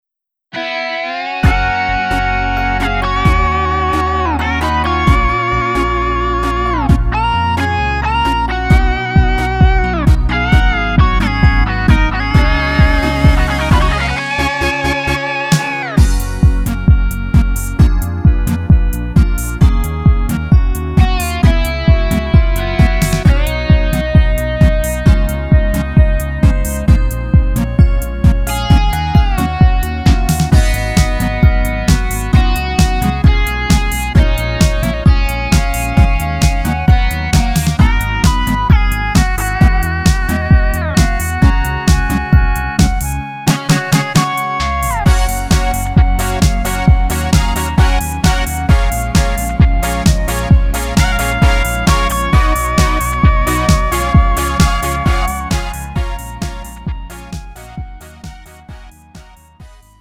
음정 -1키 2:34
장르 구분 Lite MR